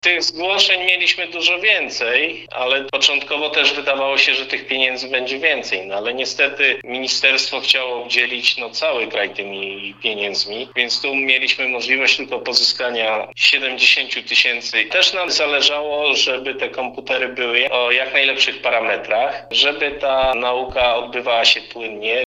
– Wypożyczane będą one uczniom, którzy są w trudnej sytuacji materialnej i nie dysponują odpowiednim sprzętem, który pozwala im w pełni uczestniczyć w zajęciach on-line – informuje wójt Leszek Surdy